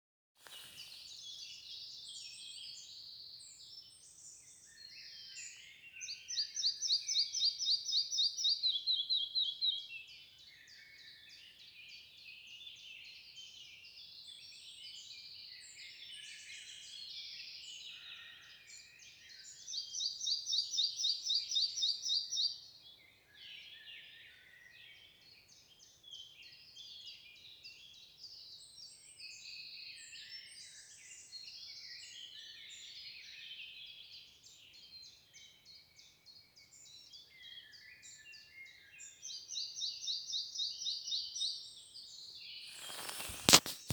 Птицы -> Мухоловковые ->
малая мухоловка, Ficedula parva
СтатусПара в подходящем для гнездования биотопе